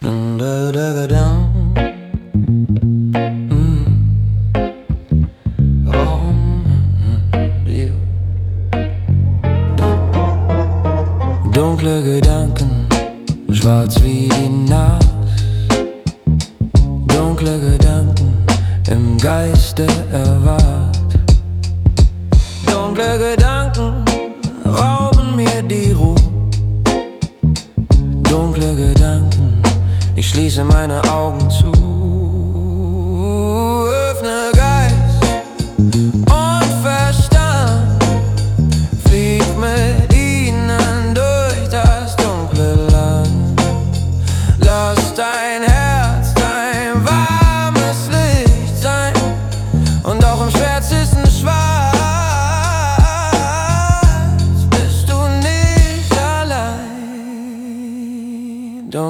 Genre: Blues